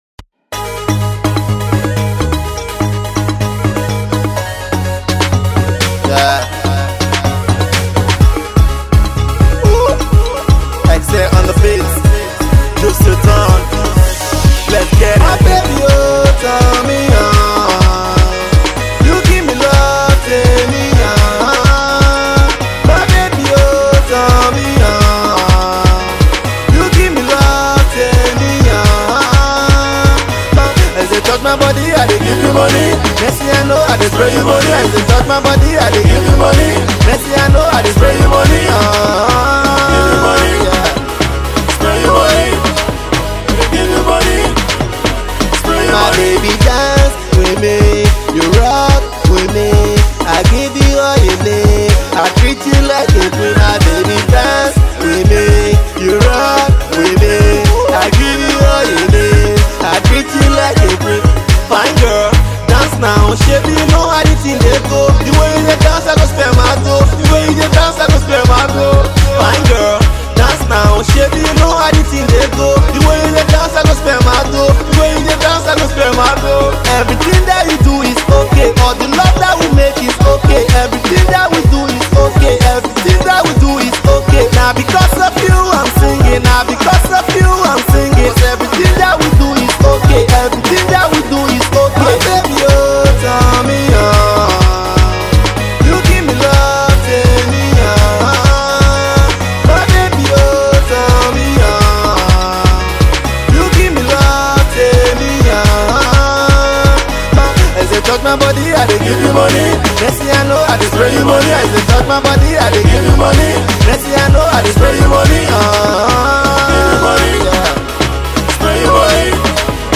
hip-pop/RnB singer
melodious single